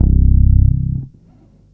2-C1.wav